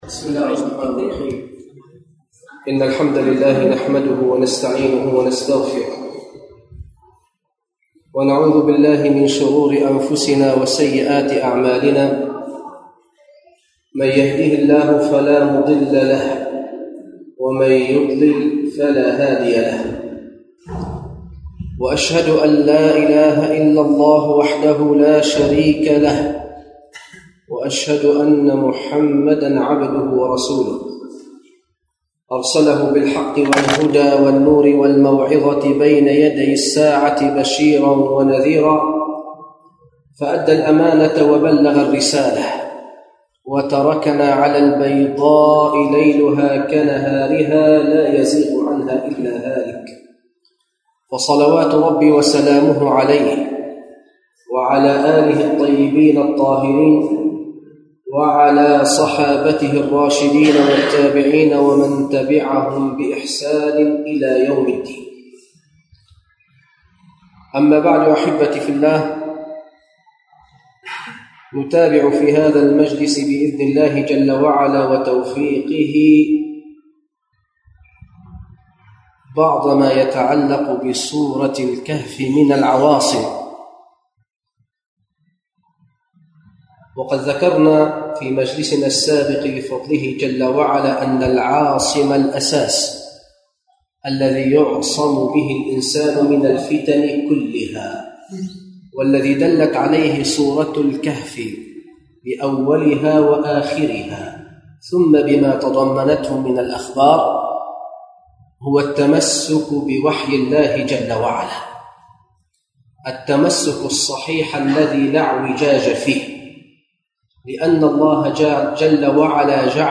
المكان: مسجد القلمون الغربي